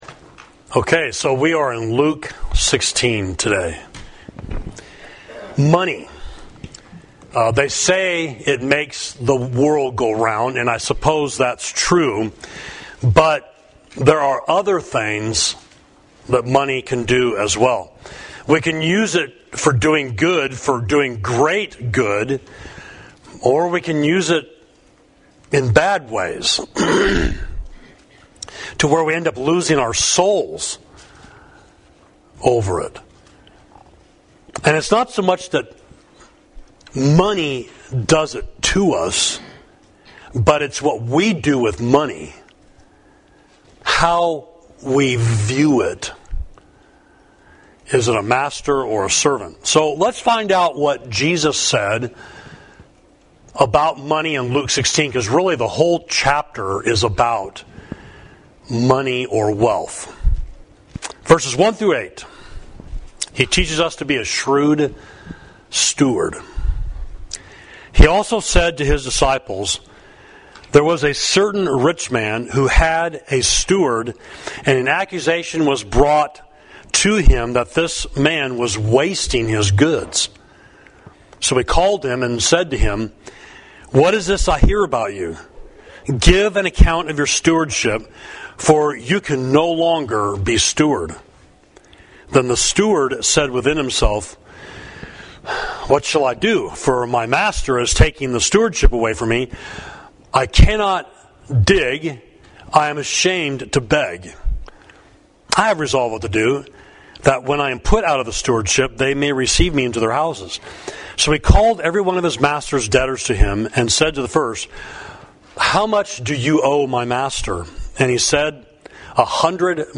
Sermon: Money in the Life of a Disciple of Christ, Luke 17